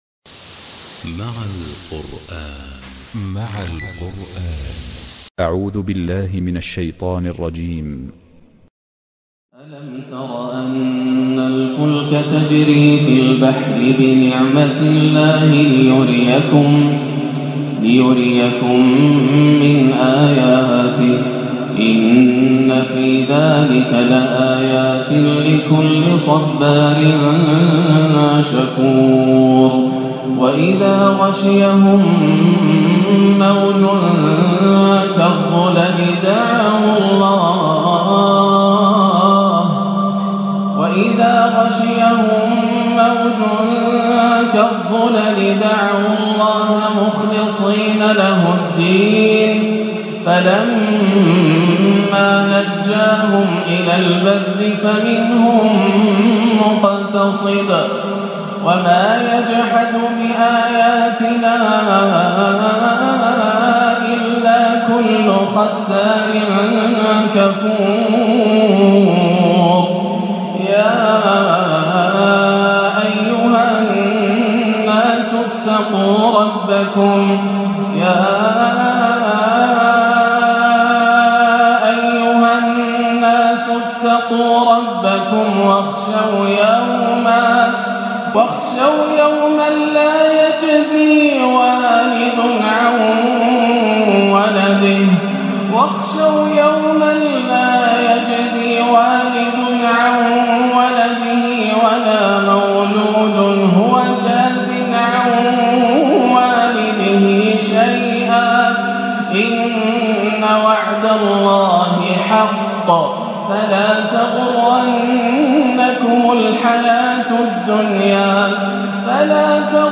أواخر سورة لقمان - عشائية عاد فيها شيخنا الغالي لجامع الدخيل بعد الحرم المكي - 15-11 > عام 1437 > الفروض - تلاوات ياسر الدوسري